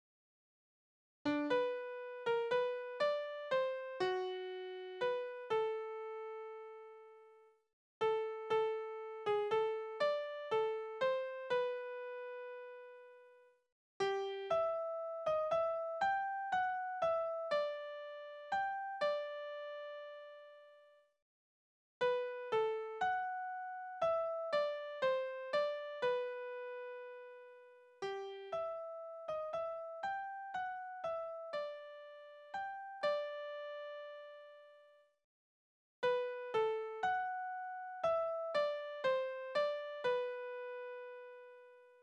Naturlieder
Tonart: G-Dur
Taktart: 3/4, 2/4
Tonumfang: Oktave, Quarte
Besetzung: vokal